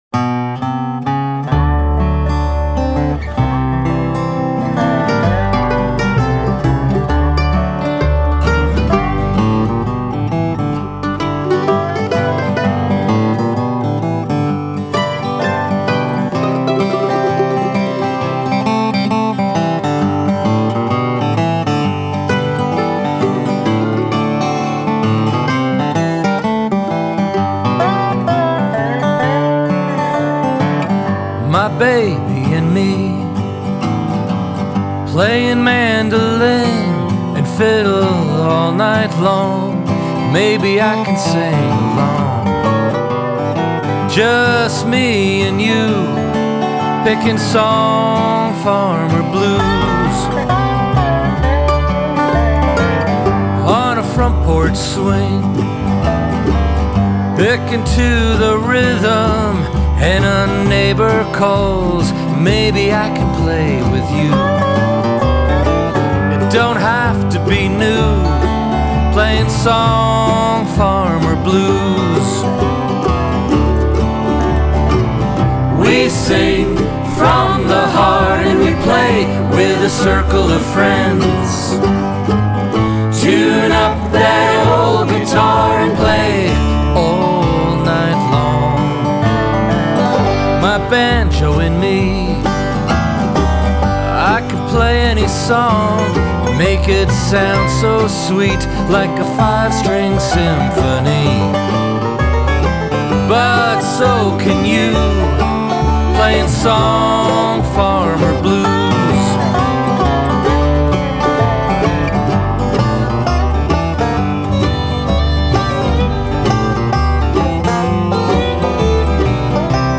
on banjo
on dobro
mandolin
fiddle
guitar and vocals